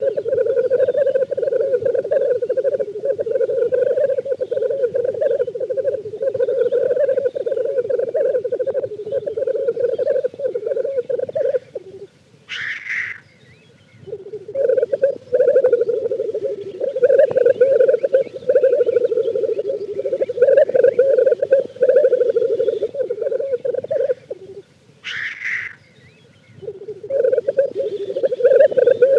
Дикуша – Falcipennis falcipennis (Hartlaub , 1855) Отряд Курообразные – Galliformes Семейство Тетеревиные – Tetraonidae Статус : III категория.
a215_03_nebolshoi_tok.mp3